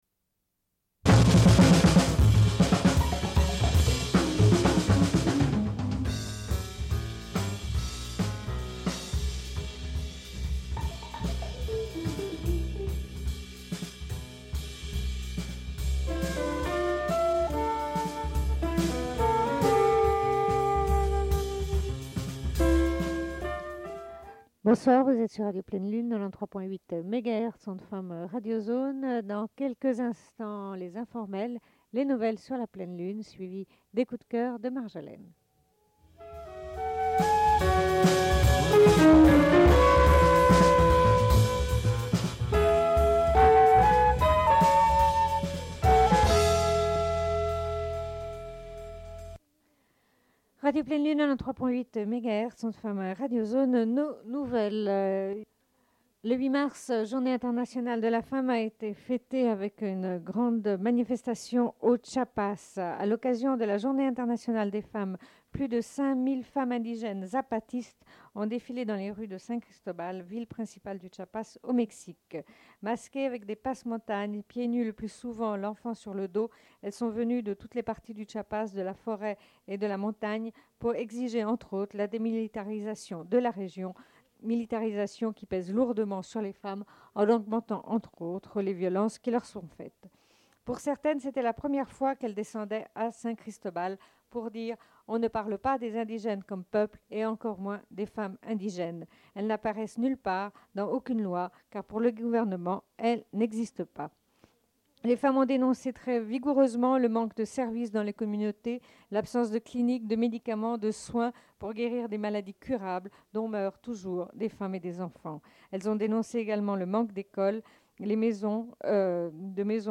Bulletin d'information de Radio Pleine Lune du 13.03.1996 - Archives contestataires
Une cassette audio, face B